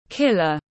Kẻ giết người tiếng anh gọi là killer, phiên âm tiếng anh đọc là /ˈkɪl.ər/.
Killer /ˈkɪl.ər/